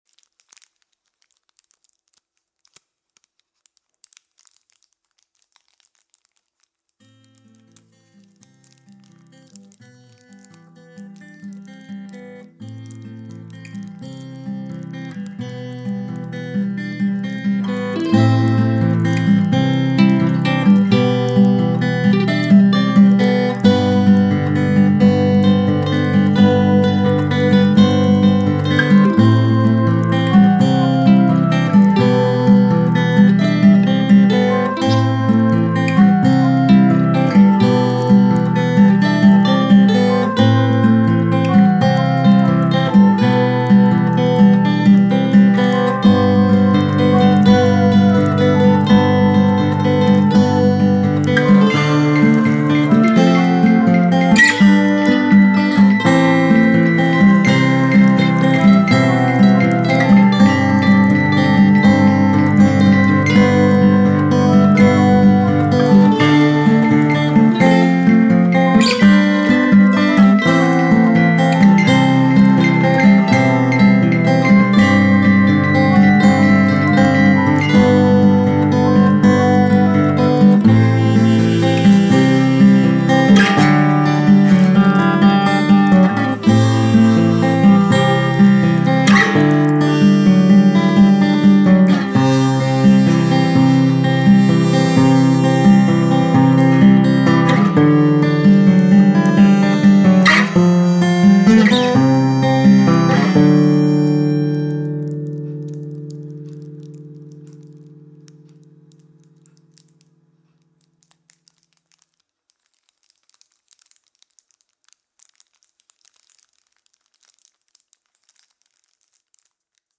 guitar
banjo
bulbul tarang